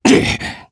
Ezekiel-Vox_Damage_jp_01_b.wav